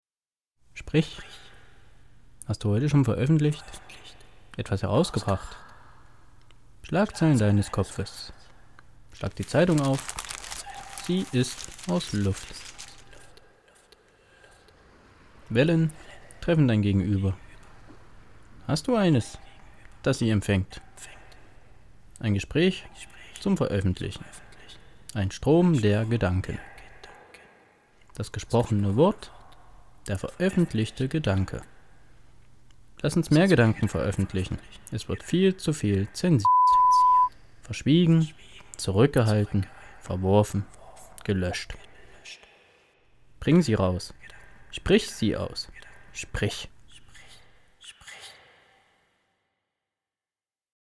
toll vertont..und bearbeitet..ein kleines Hörspiel